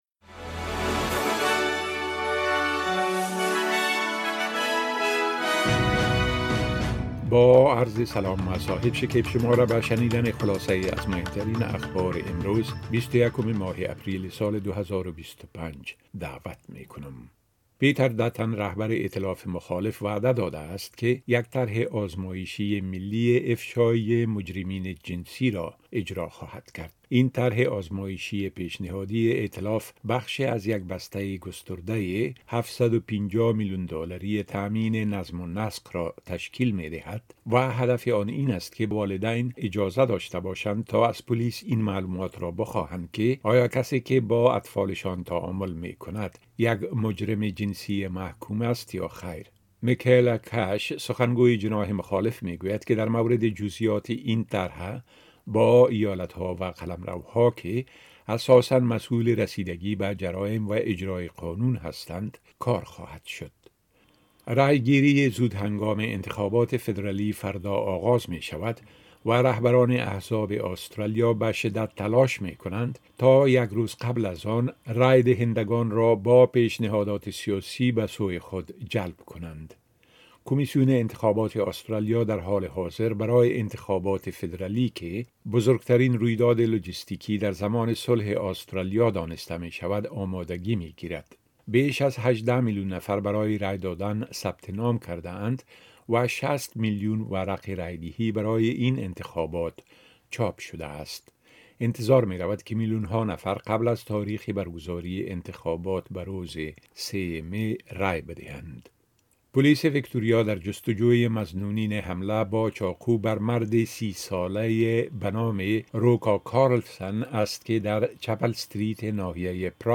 خلاصۀ مهمترين اخبار روز از بخش درى راديوى اس بى اس